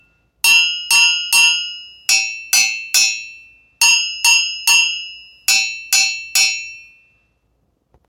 ギニア製鉄ベル ドゥンドゥン用 2点セット アフリカンベル （p600-25） - アフリカ雑貨店 アフロモード
説明 この楽器のサンプル音 原産国 ギニア 材質 鉄 サイズ 高さ：15cm 幅：9cm奥行：6cm 高さ：15cm 幅：8cm奥行：5cm 重量 810g コメント 鉄スティック2本付。